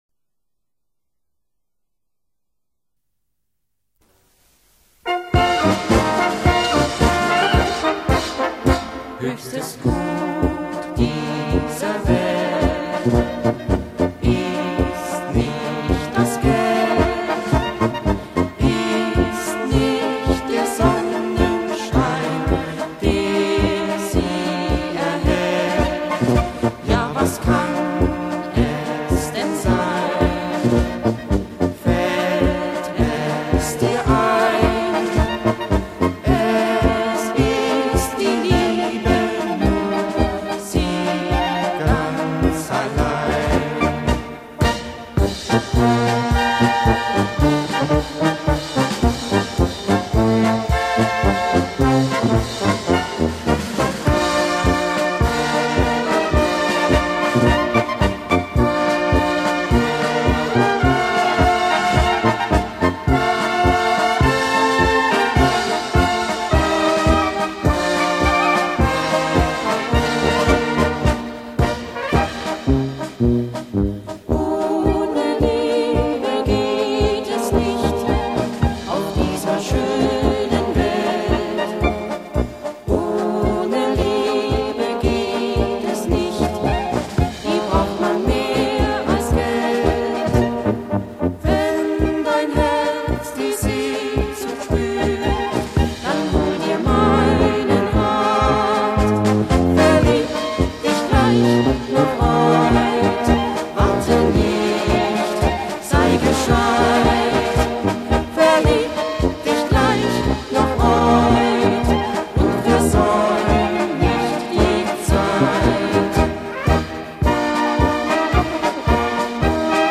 Polka mit Gesang